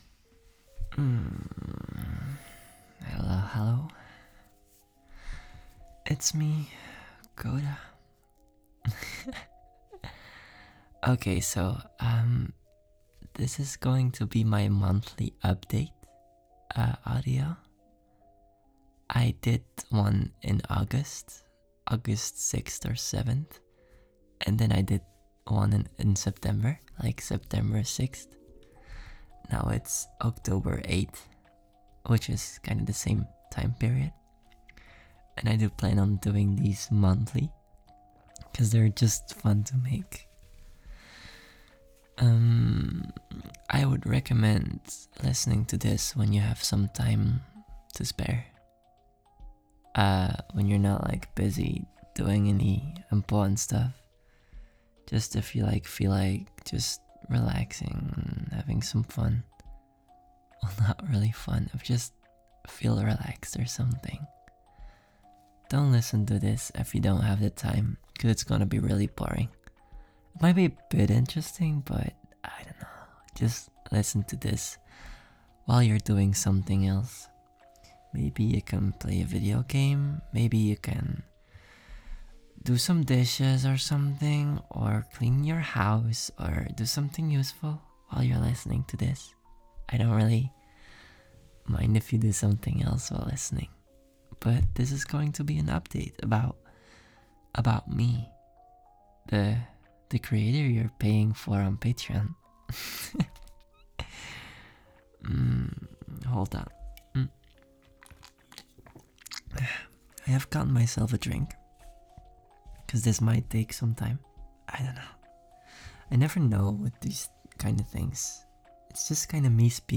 Your voice is so calming .